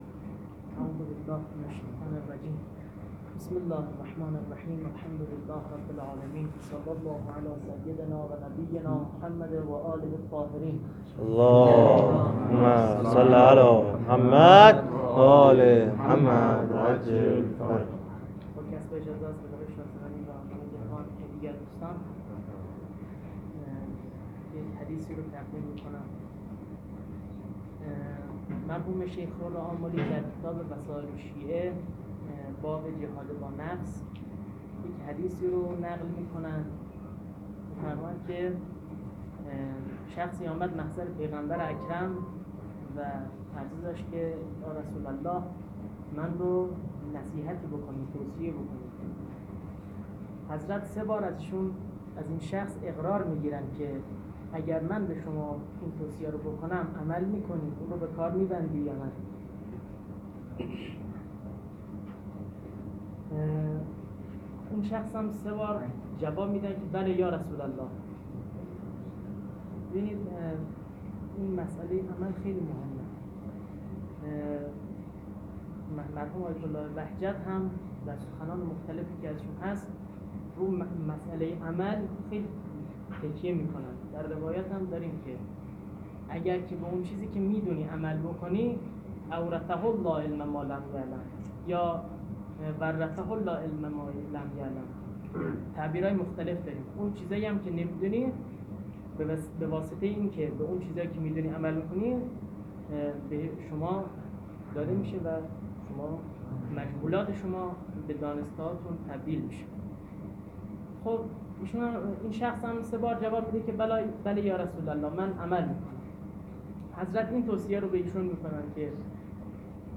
سخنرانی حوزه علمیه محمدیه.m4a
سخنرانی-حوزه-علمیه-محمدیه.m4a